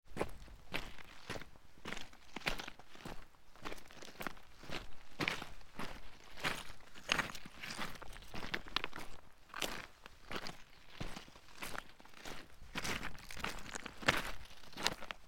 دانلود آهنگ کوه 1 از افکت صوتی طبیعت و محیط
دانلود صدای کوه 1 از ساعد نیوز با لینک مستقیم و کیفیت بالا
جلوه های صوتی